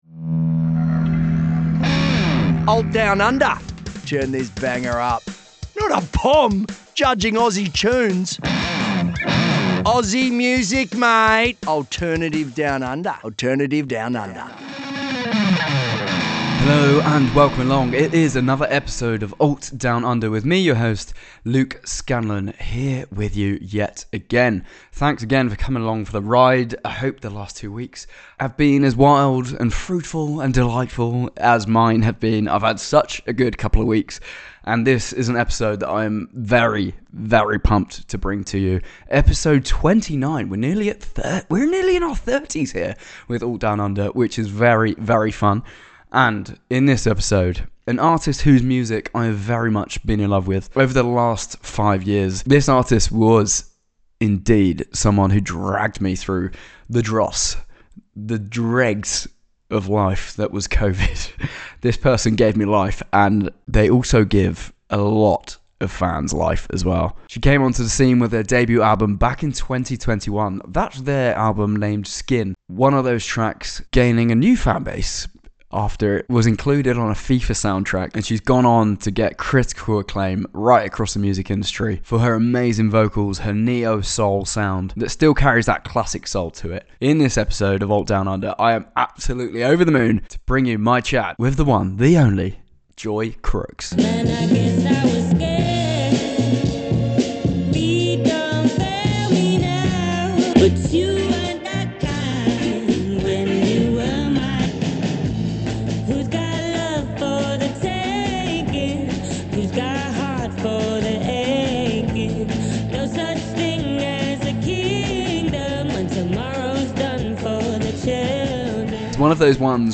Joy Crookes chats Juniper, whirlwind love stories, Kano, and finding romance in life’s smaller moments - plus a few attempts at an Aussie accent.